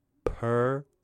描述：Syllable: "Pull" from purple, with male voice
标签： Auditory Male Training Pull
声道立体声